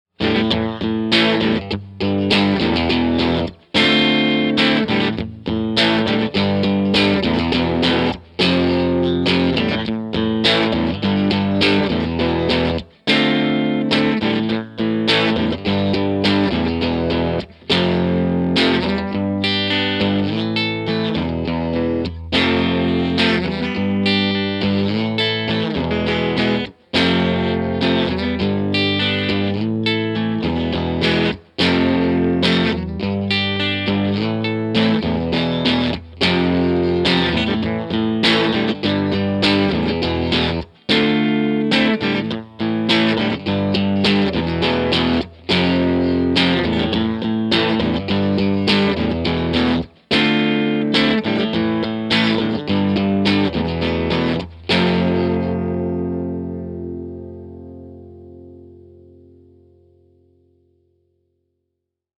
Mic was a single SM57, to Vintech (Neve-style) pre, to Apogee Rosetta 200 A/D, to the computer.
Ch.2 Dirty was as follows - no MV, cut 2:00, treble 10:30, bass 1:00, vol 10:30, munch/hi on the back, Lo input on the front.
TC15_Ch2_Dirty_Ric_Bridge.mp3